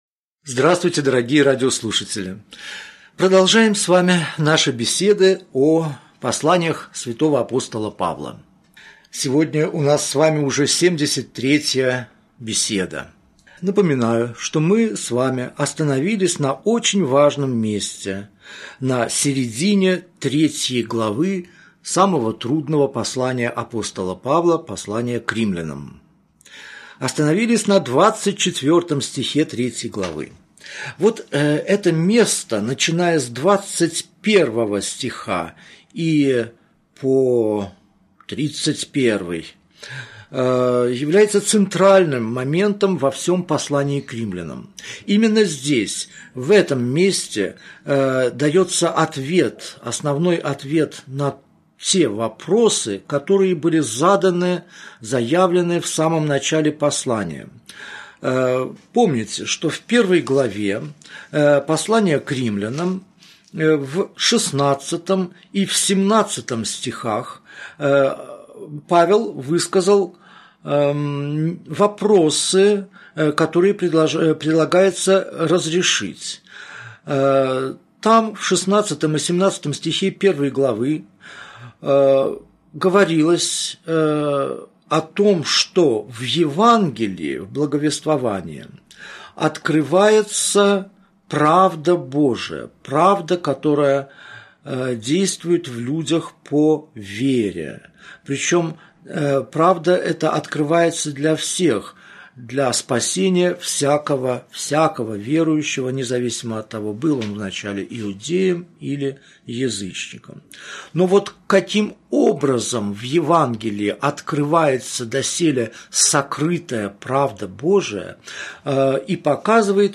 Аудиокнига Беседа 73. Послание к Римлянам. Глава 3, стих 21 – глава 4 | Библиотека аудиокниг